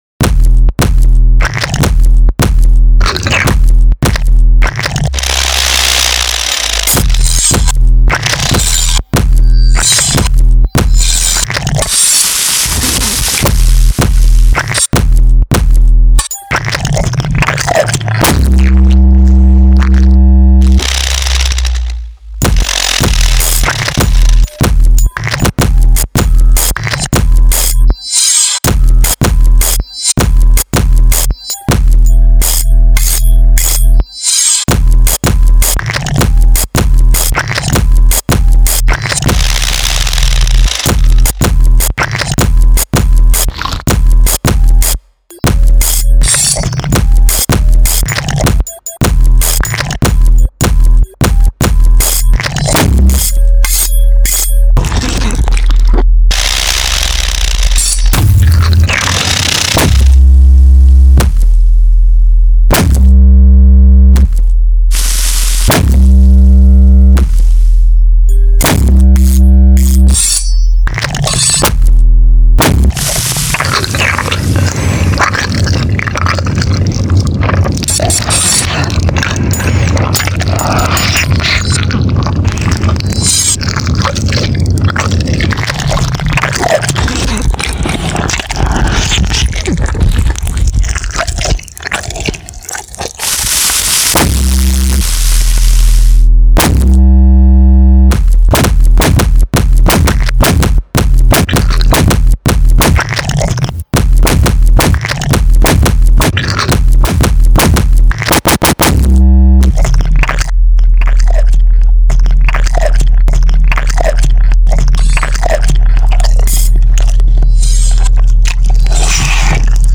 One is sort of a pop song with vocals, and one is an instrumental that samples the sound of meat (chewing, frying, cutting, pounding, grinding).
So it should be industrial (sound of technology/machinery) and synthetic (synthesized sounds).